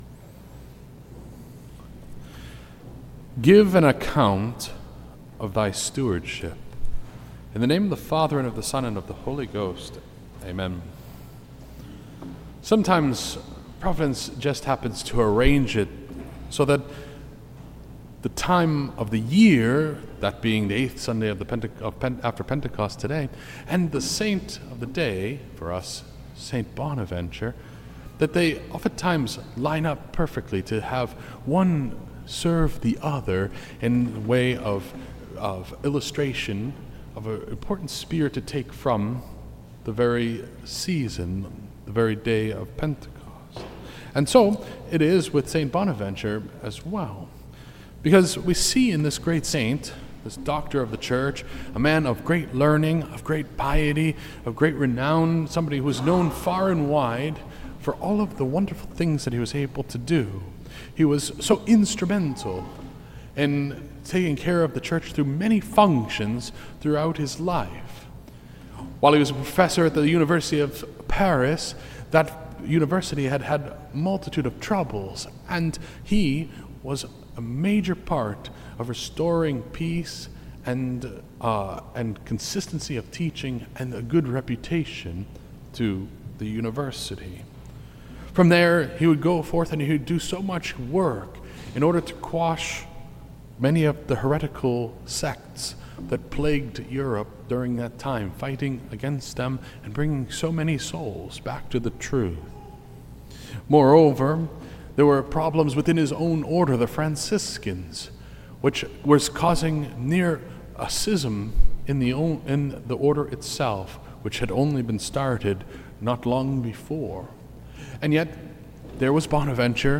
This entry was posted on Sunday, July 14th, 2024 at 5:23 pm and is filed under Sermons.